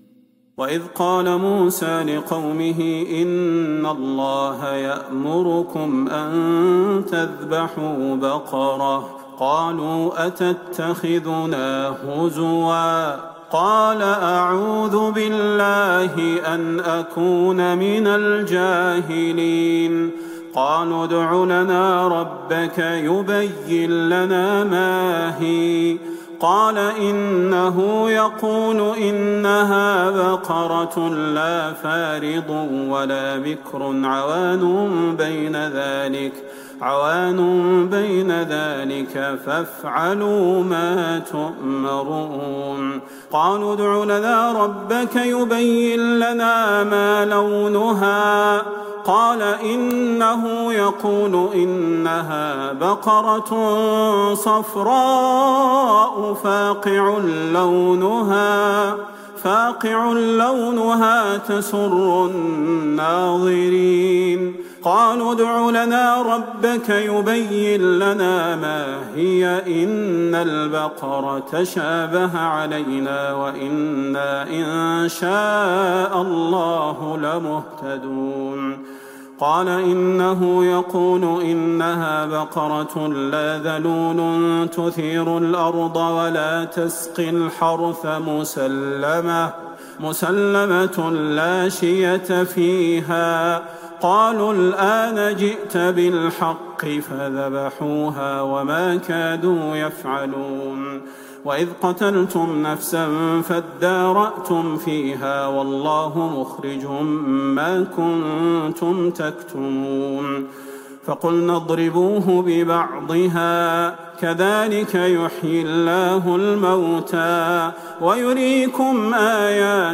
ليلة ١ رمضان ١٤٤١هـ من سورة البقرة { ٦٧-١٠٥ } > تراويح الحرم النبوي عام 1441 🕌 > التراويح - تلاوات الحرمين